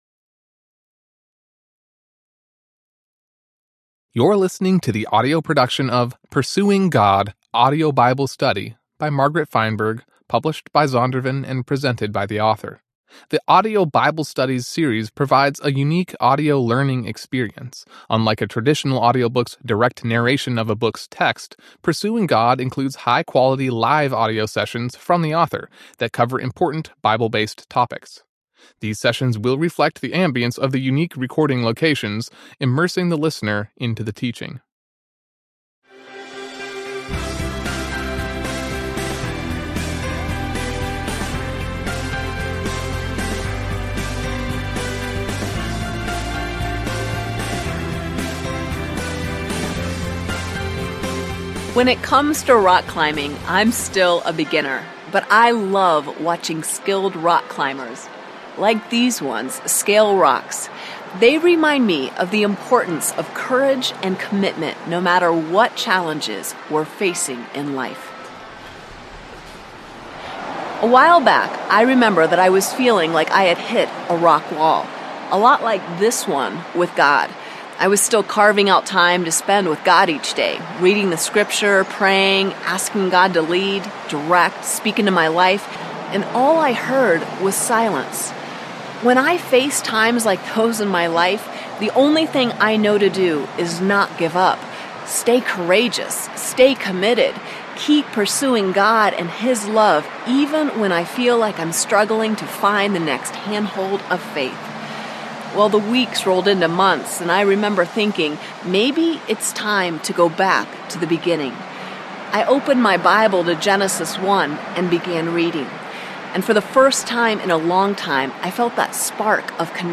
The Bible Study Source series provides a unique audio learning experience.
These sessions will reflect the ambiance of the unique recording locations, immersing the listener into the teaching.